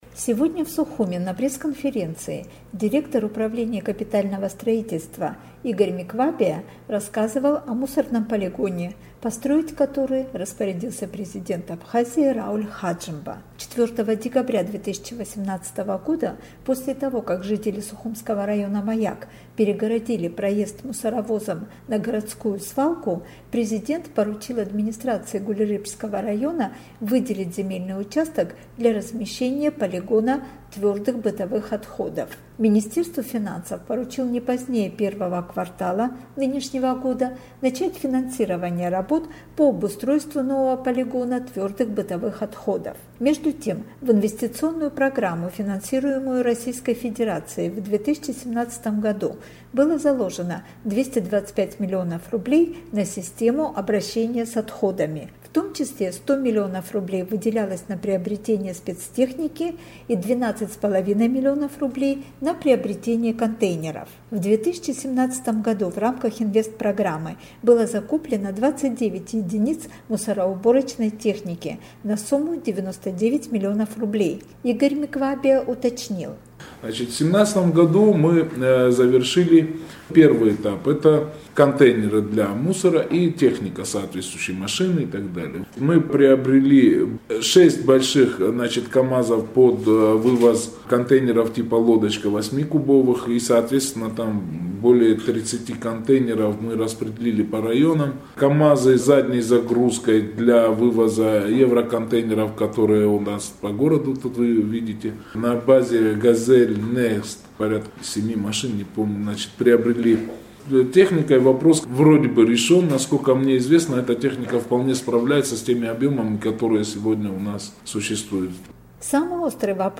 Руководитель Управления капитального строительства Игорь Миквабия провел сегодня пресс-конференцию